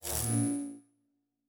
pgs/Assets/Audio/Sci-Fi Sounds/Electric/Device 7 Stop.wav at master
Device 7 Stop.wav